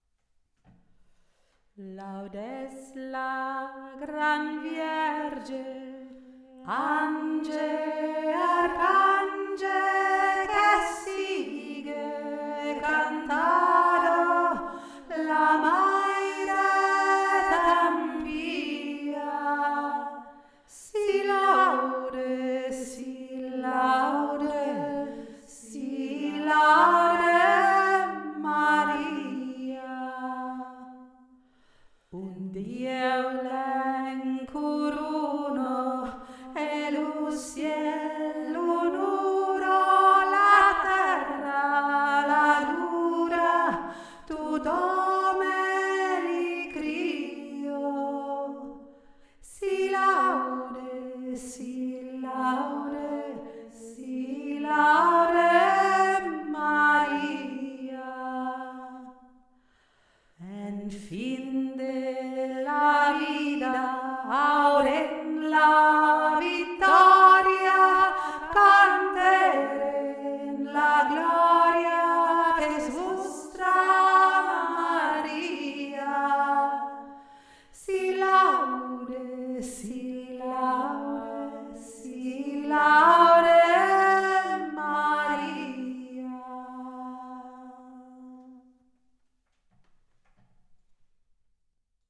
SI LAUDE MARIA - sardisches Marienlied
Sie Laude Hautpstimme